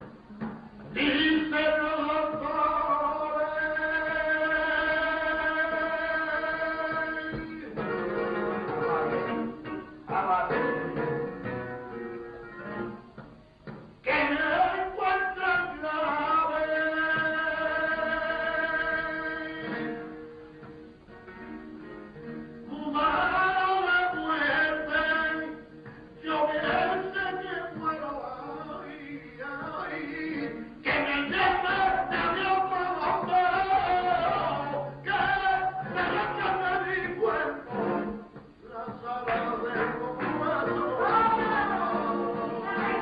Tientos (otros)